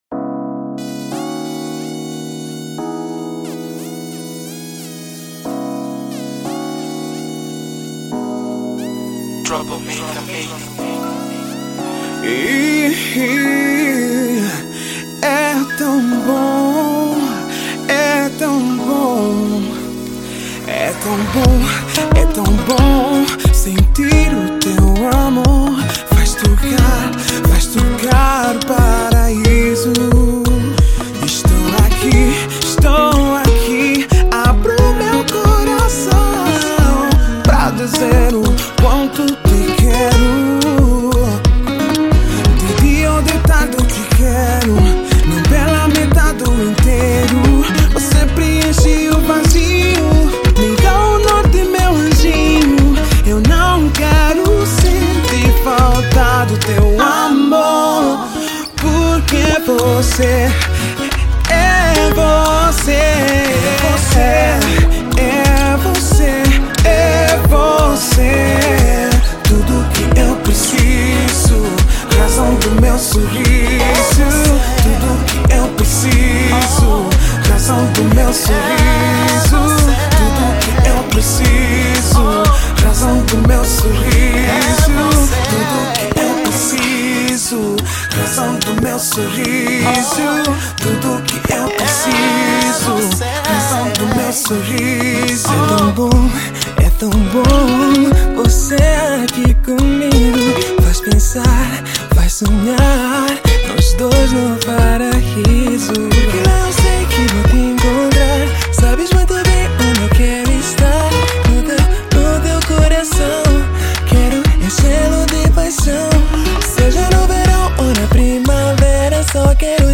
00:03:03 | MP3 | 320bps | 03:0MB | Kizomba